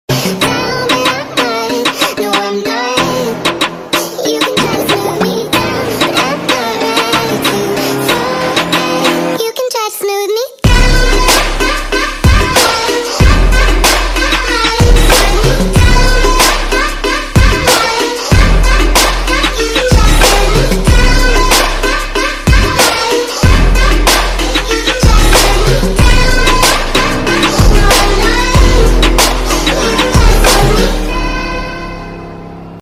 Electronic/Pop. It has a dreamy and emotional vibe
soulful vocals
vibrant electronic production